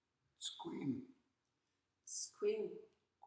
These words and greetings in Hul’q’umi’num’ were recorded by a district elder and are offered as a way to learn and practice the language.